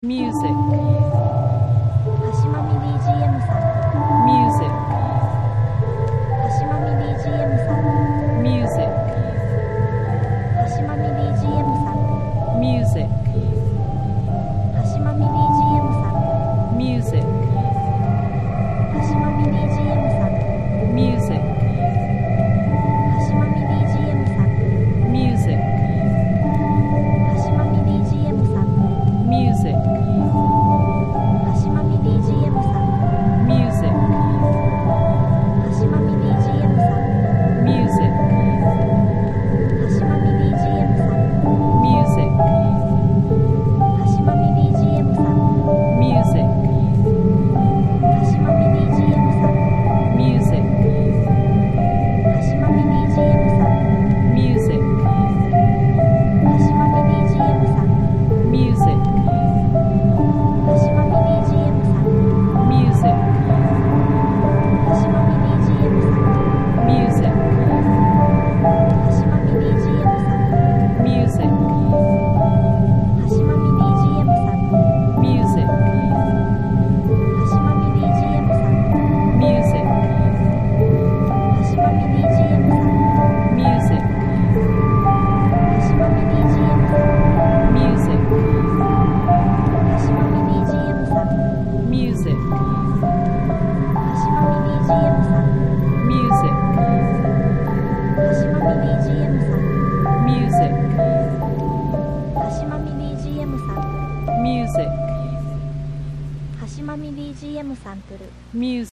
ピアノと環境音が中心の不穏なホラー曲